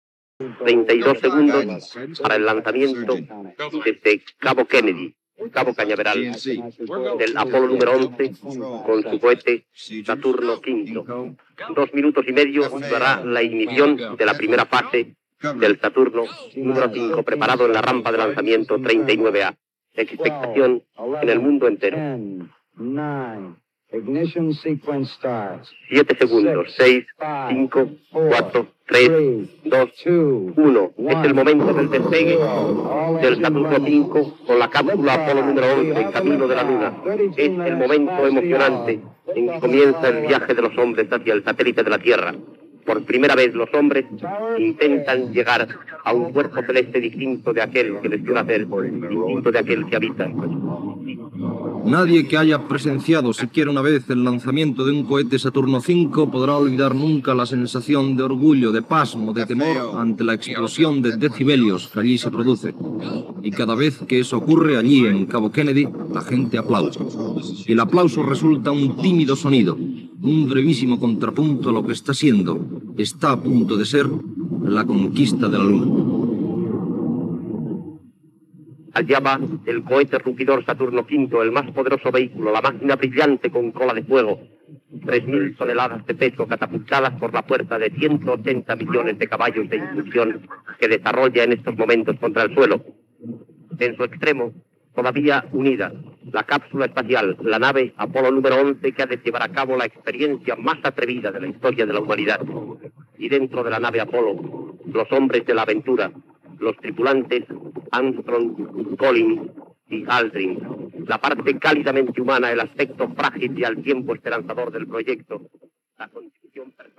Transmissió del llançament de l'Apollo XI pel cohet Saturn V des de Cap Canaveral (Florida, EE.UU.)
Informatiu